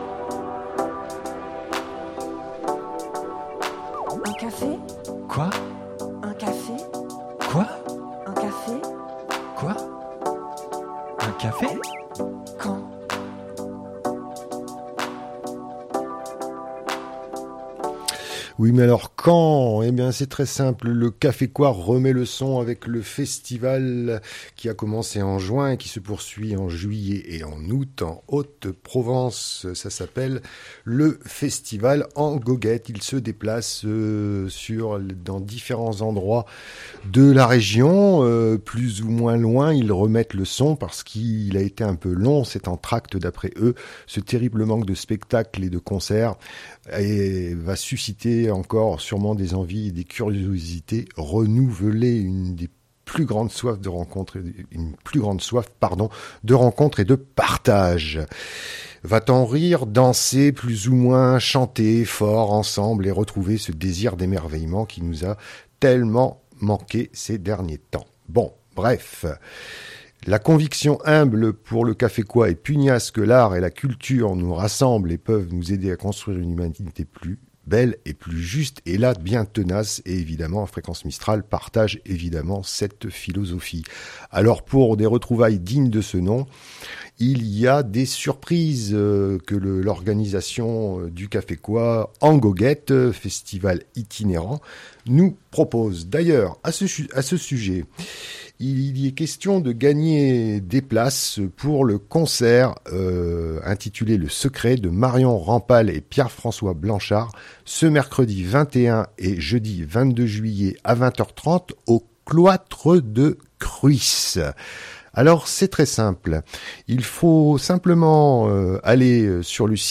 Le Secret révèle des affinités entre mélancolie romantique et blues, conjugue dans un geste musical intempestif et créateur deux modernités qui ont fécondé le vingtième siècle.
Vous entendrez un extrait musical de ces deux singuliers artistes...